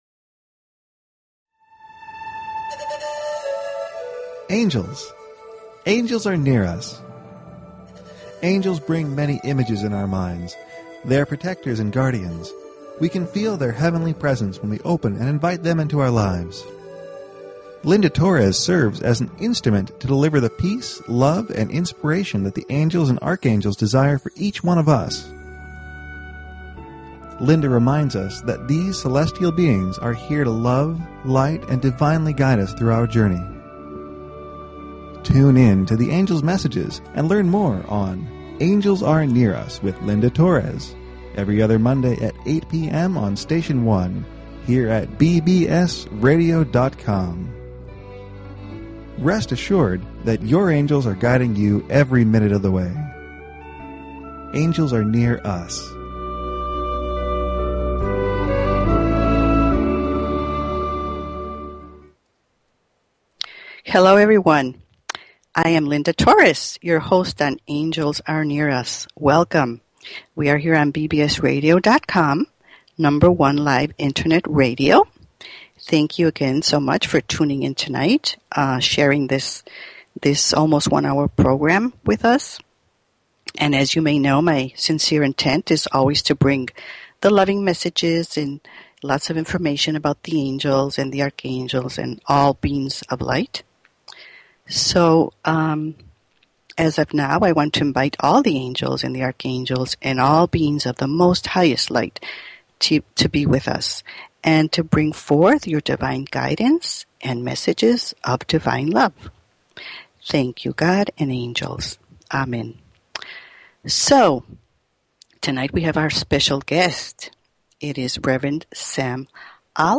Talk Show Episode, Audio Podcast, Angels_Are_Near_Us and Courtesy of BBS Radio on , show guests , about , categorized as
The last 30 minutes of the show the phone lines will be open for questions and Angel readings.